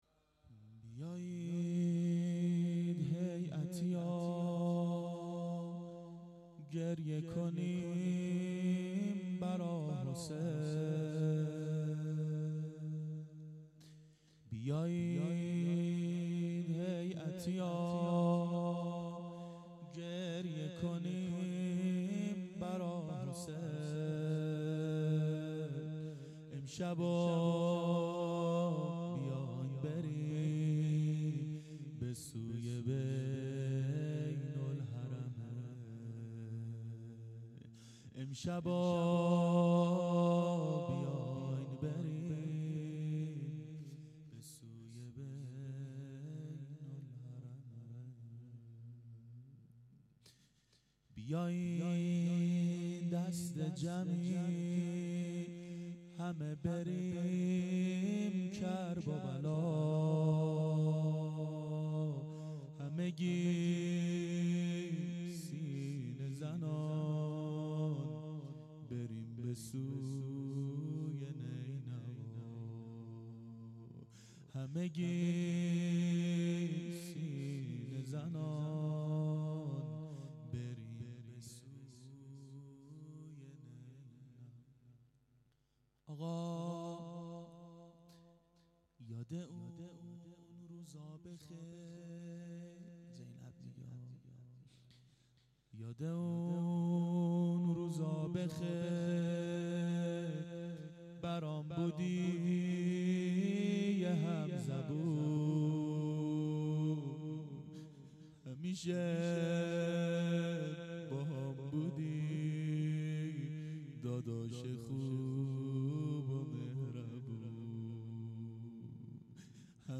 روضه
دهه اول صفر سال 1391 هیئت شیفتگان حضرت رقیه سلام الله علیها (شب اول)